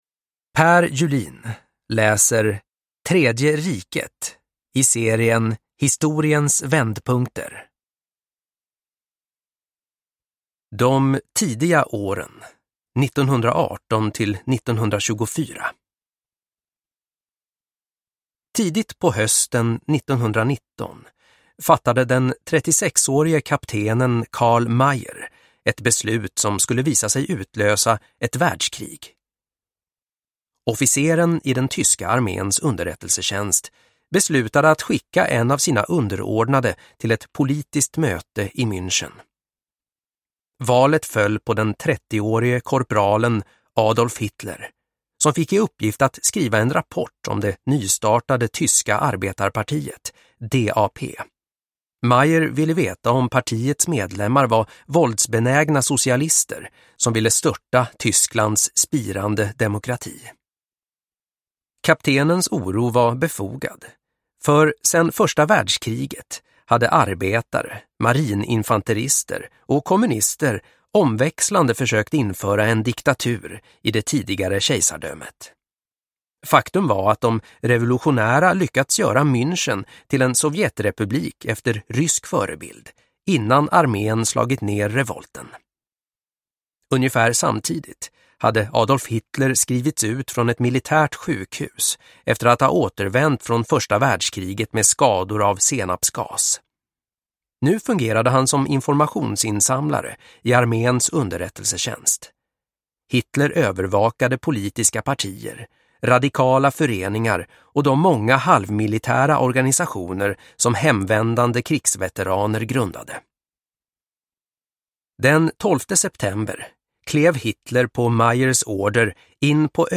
Tredje riket – Ljudbok